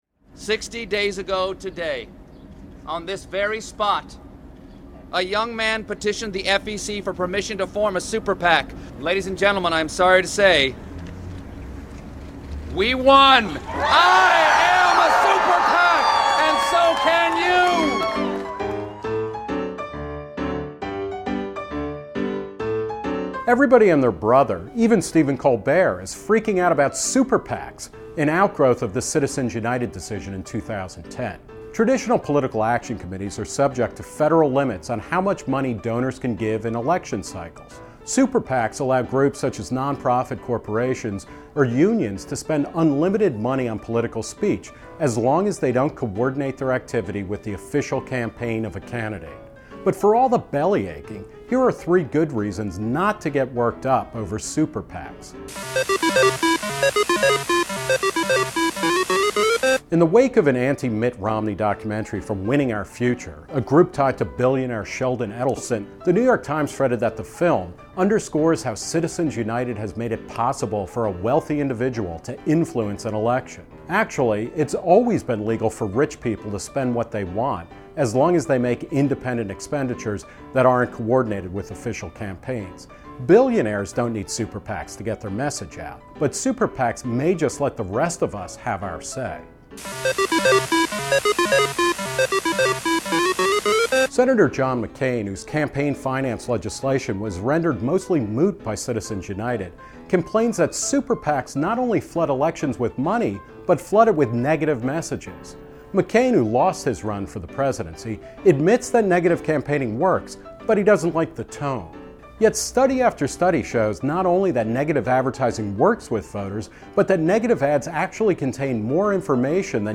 Written and narrated by Nick Gillespie.